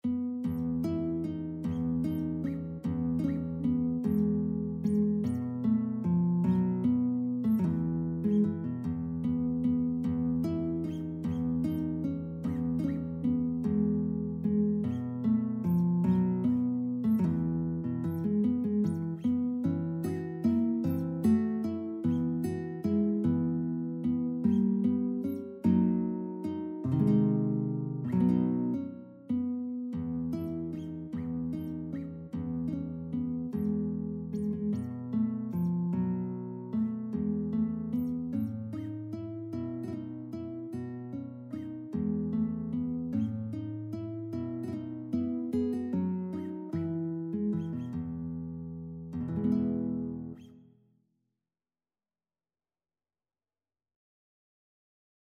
3/8 (View more 3/8 Music)
Andantino . = c.50 (View more music marked Andantino)
E3-Ab5
Guitar  (View more Easy Guitar Music)
Classical (View more Classical Guitar Music)